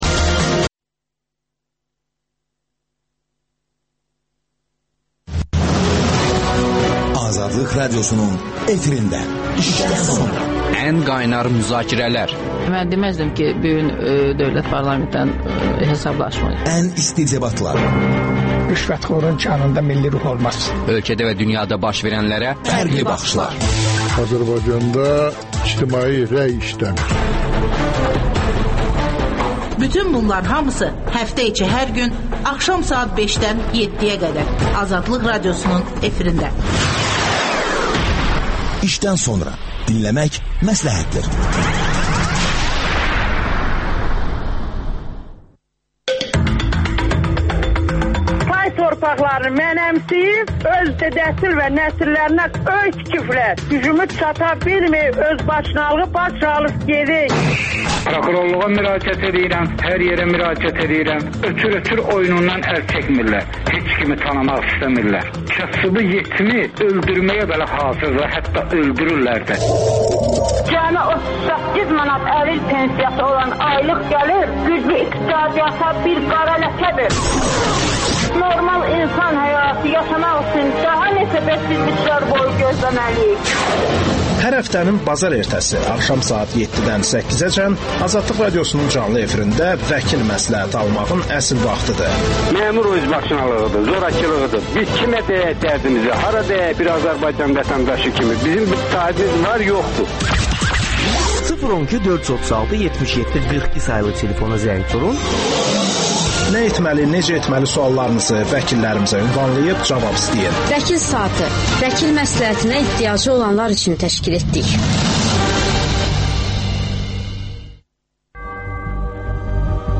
Aparıcı və dinləyicilərin suallarını